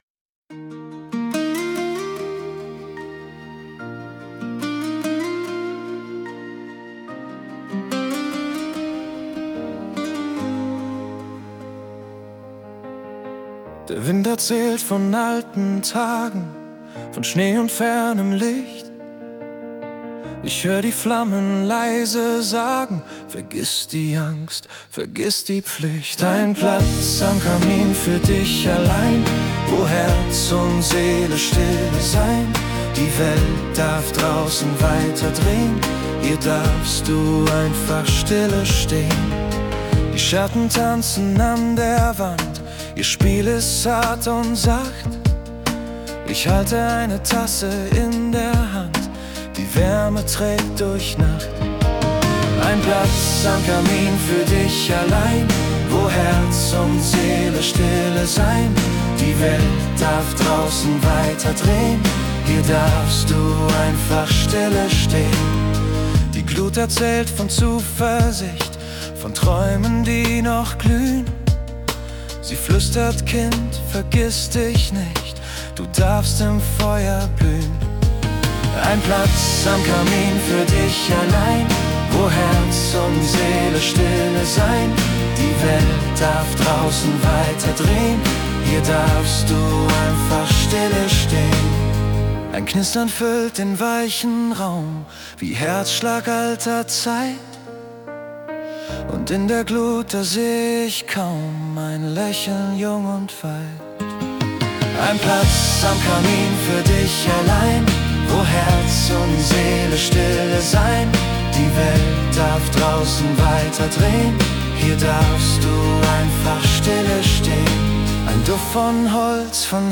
Ein Klang wie eine Decke aus Licht Trost und Nähe im Herzen.